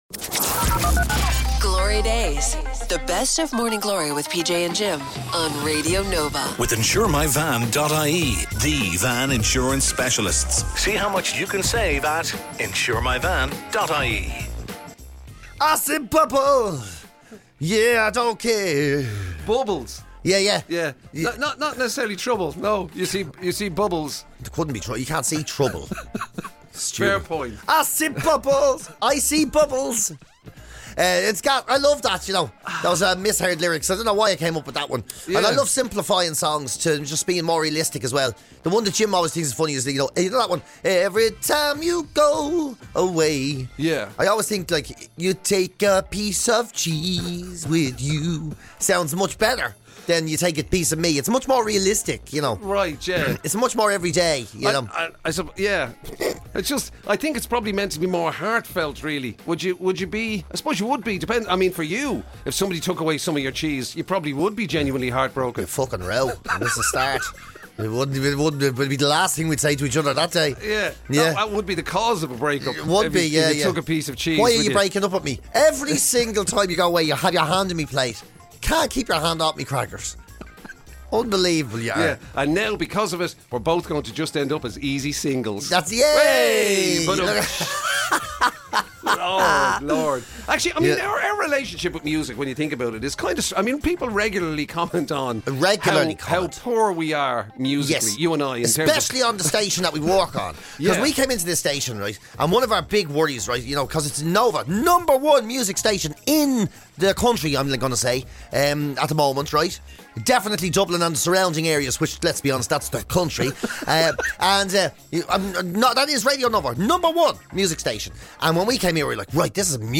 Catch some of the best bits and more from Morning Glory
… continue reading 231 episodes # Comedy # Radio Nova